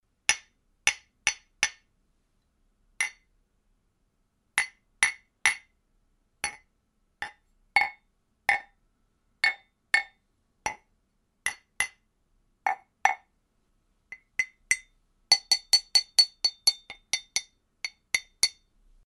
• mug hits - glass ear candy.mp3
Recorded with a Steinberg Sterling Audio ST66 Tube, in a small apartment studio.
mug_hits_-_glass_ear_candy_mhl.wav